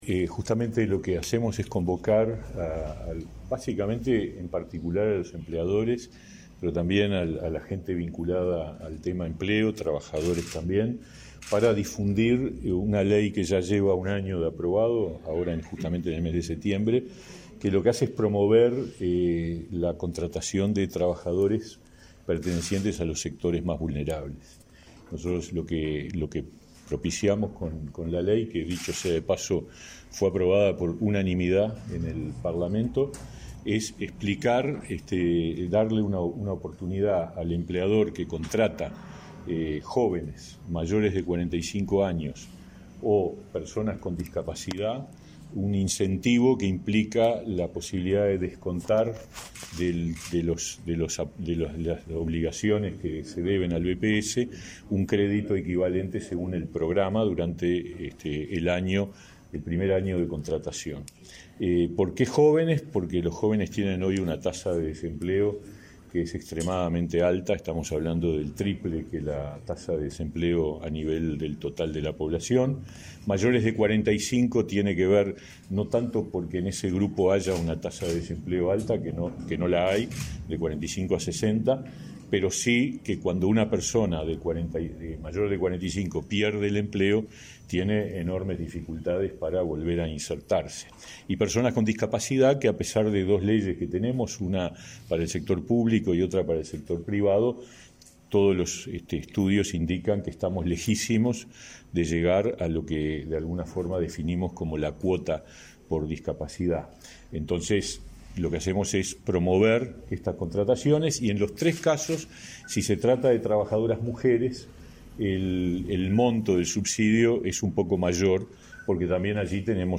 Declaraciones a la prensa del ministro de Trabajo y Seguridad Social, Pablo Mieres
Declaraciones a la prensa del ministro de Trabajo y Seguridad Social, Pablo Mieres 08/09/2022 Compartir Facebook X Copiar enlace WhatsApp LinkedIn Tras participar en un encuentro con trabajadores y empleadores de Lavalleja, este 8 de setiembre, el ministro Pablo Mieres efectuó declaraciones a la prensa.